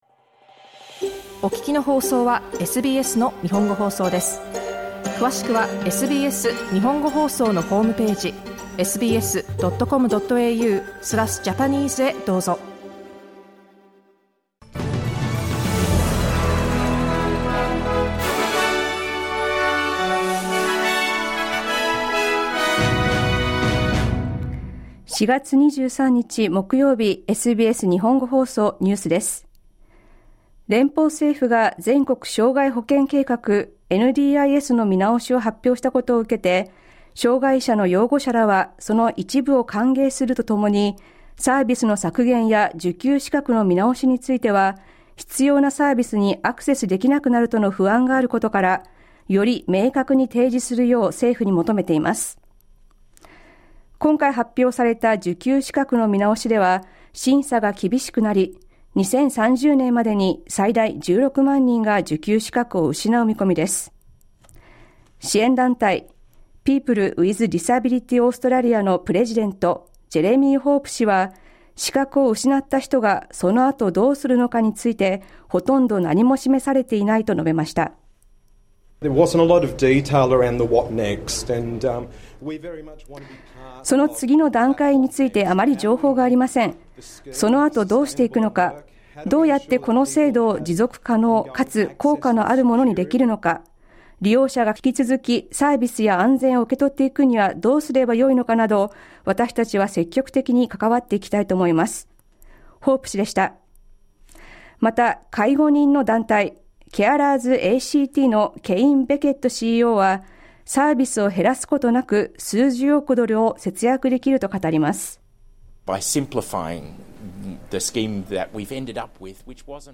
Prime Minister Anthony Albanese has played down expectations of tougher fuel restrictions, ahead of a national cabinet meeting today. The national group respresenting doctors in Australia is urging people to ensure they are staying up-to-date with vaccinations. News from today's live program (1-2pm).